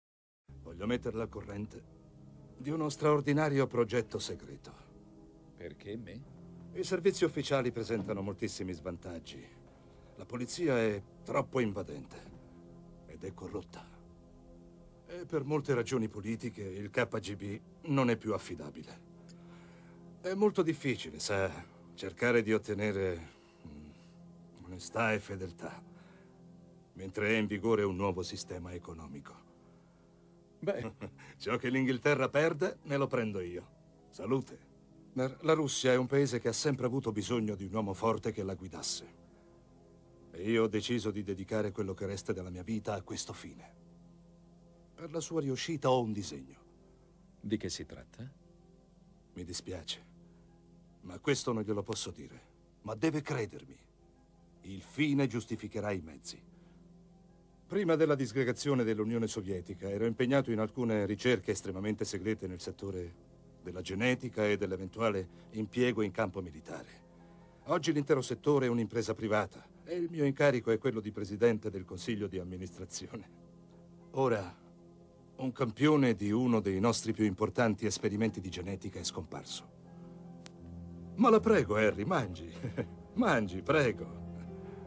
nel film "All'inseguimento della morte rossa", in cui doppia Michael Gambon.